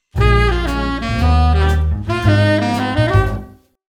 Once again, we see a group of 12 melody notes which sound as if they fit into the key of Bb major.   And again, this is part of a common bebop lick.
second-lick-of-bridge.mp3